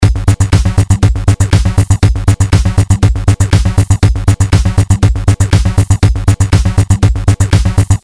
描述：合成的旋律和声音，与Pattern 3的扭曲合成器发挥得很好。
声道立体声